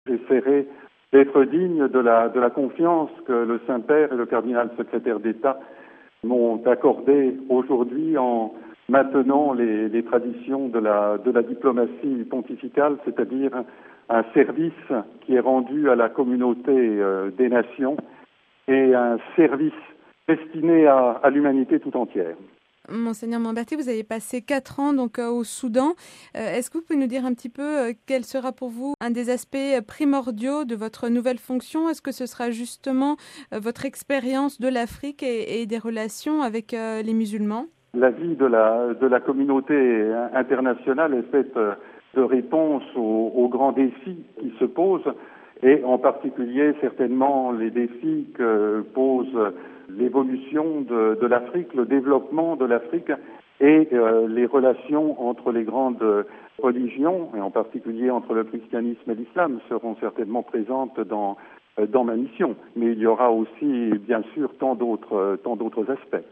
Ecoutez sa réaction recueillie hier après sa nomination RealAudio